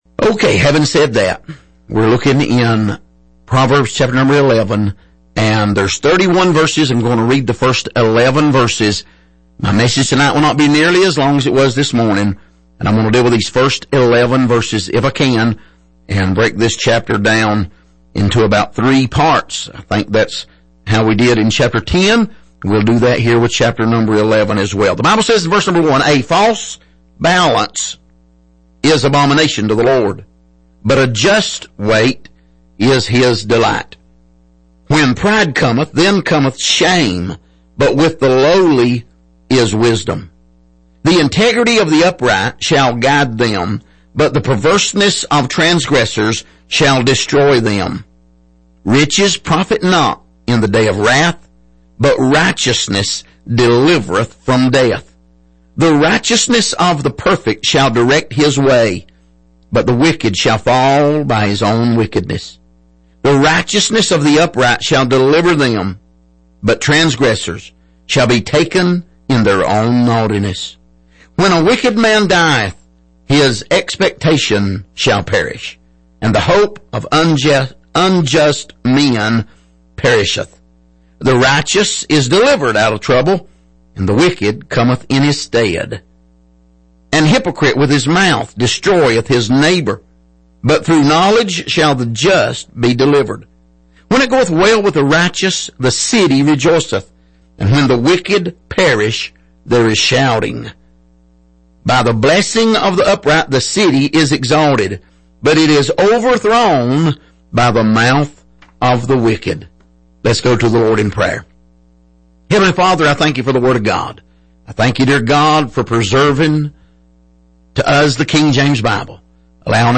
Passage: Proverbs 11:1-11 Service: Sunday Evening